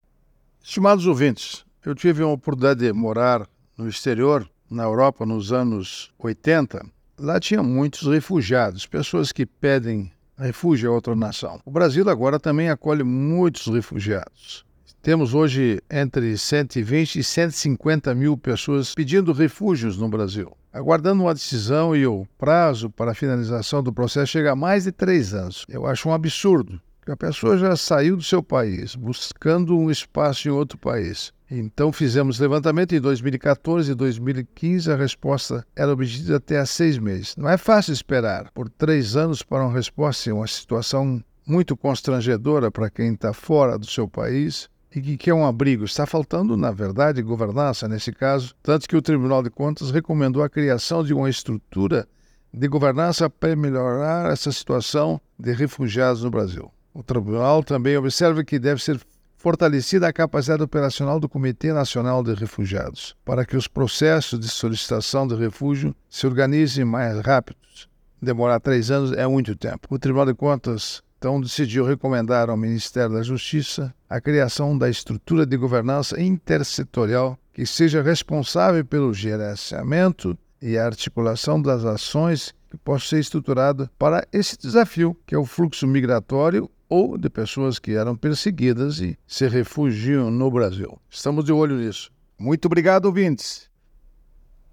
É o assunto do comentário do ministro do Tribunal de Contas da União, Augusto Nardes, desta terça-feira (18/06/24), especialmente para OgazeteirO.